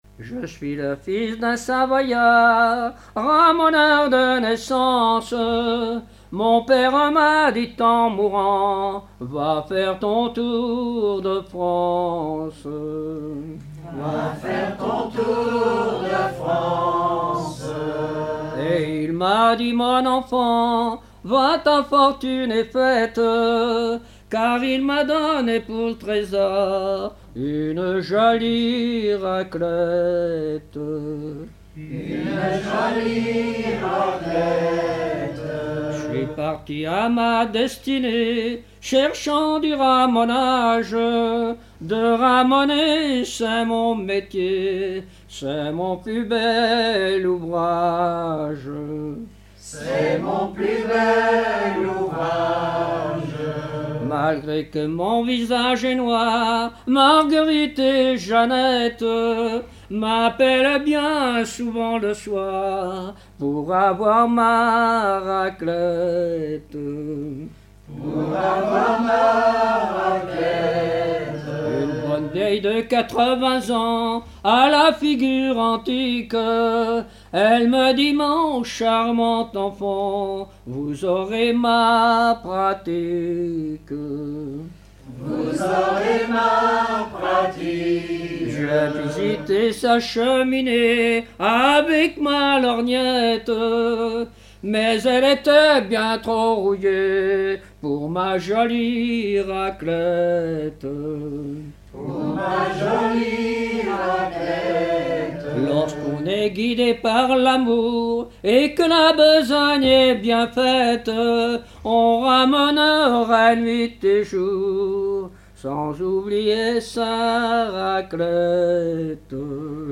Localisation Saint-Sulpice-le-Verdon
Genre laisse
Catégorie Pièce musicale inédite